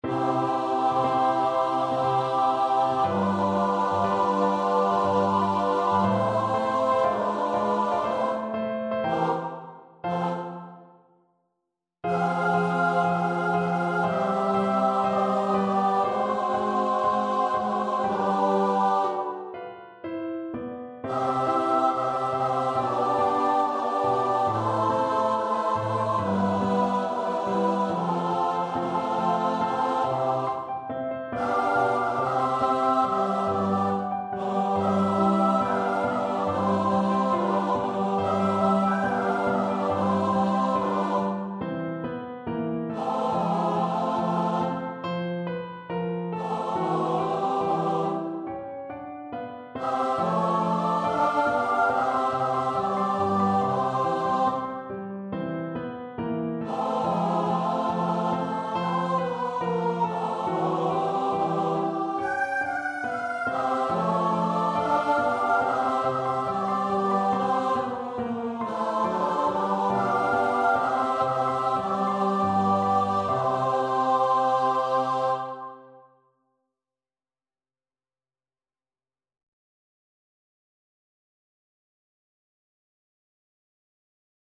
Free Sheet music for Choir (SATB)
Choir  (View more Beginners Choir Music)
Classical (View more Classical Choir Music)